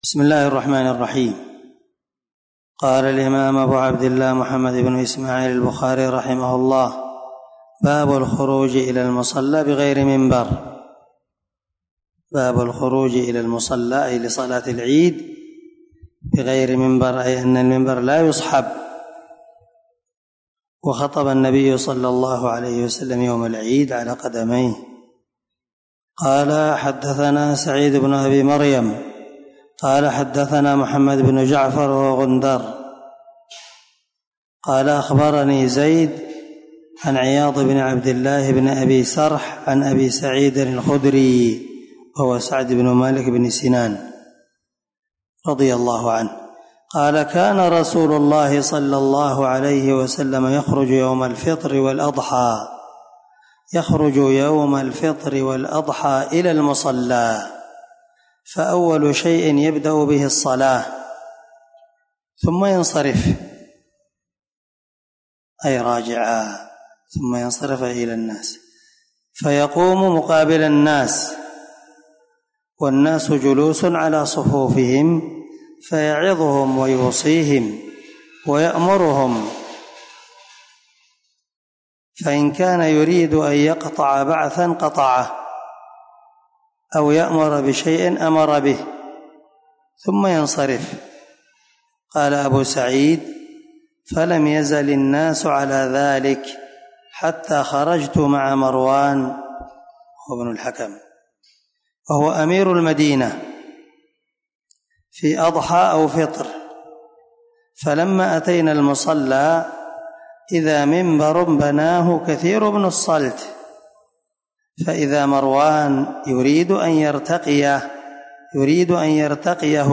595الدرس6من شرح كتاب العيدين حديث رقم(956) من صحيح البخاري